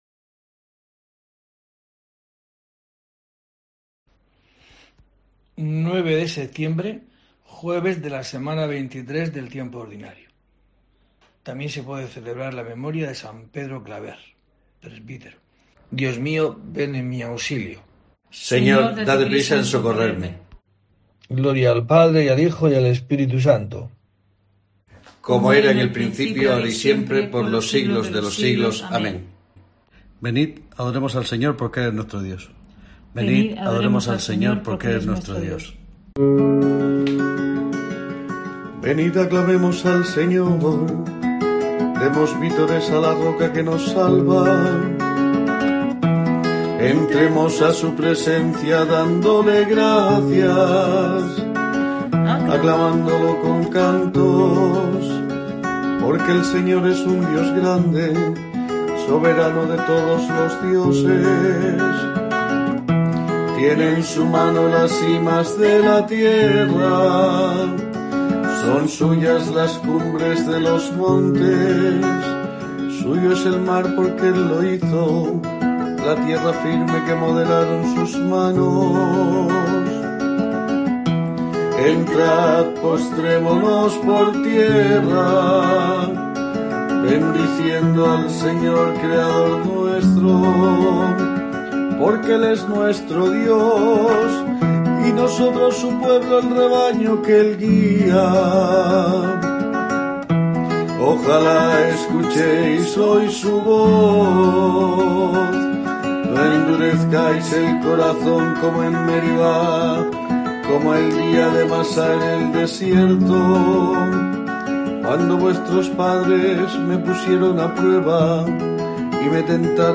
9 de septiembre: COPE te trae el rezo diario de los Laudes para acompañarte